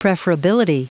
Prononciation du mot preferability en anglais (fichier audio)
Prononciation du mot : preferability